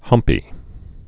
(hŭmpē)